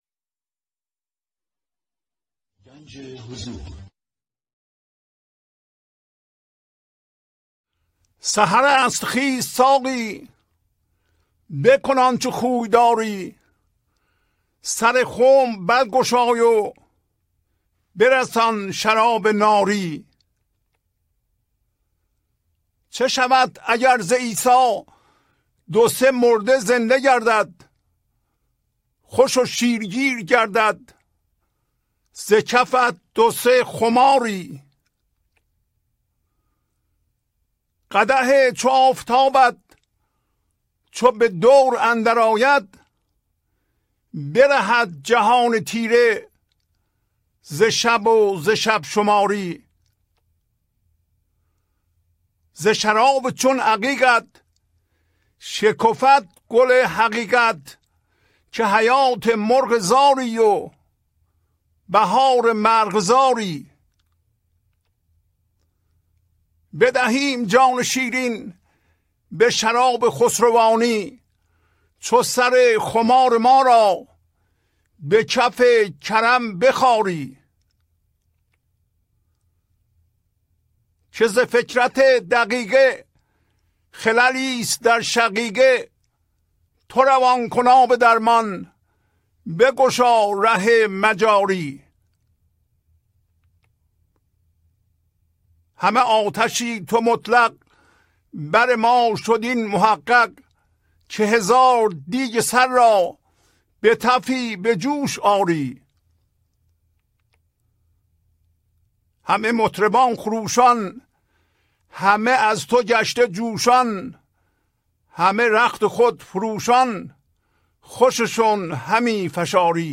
خوانش تمام ابیات این برنامه - فایل صوتی
1049-Poems-Voice.mp3